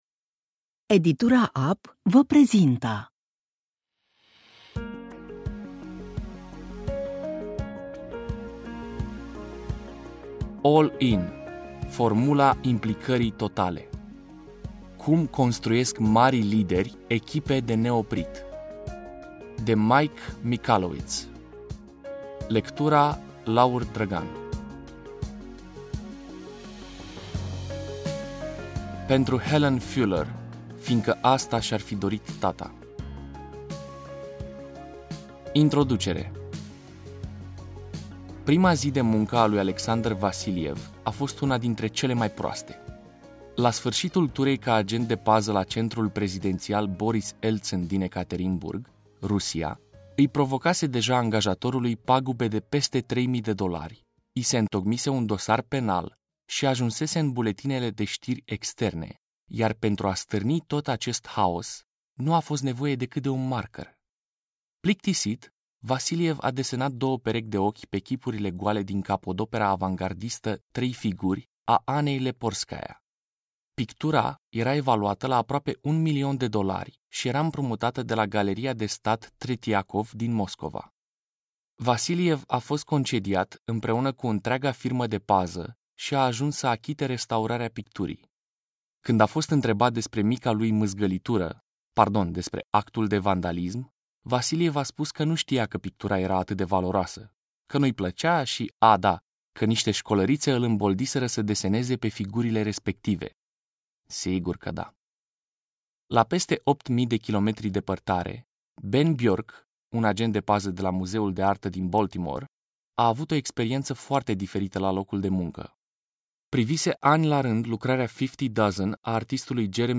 ASCULTAȚI GRATUIT UN FRAGMENT
mono Poate fi descarcat pe orice dispozitiv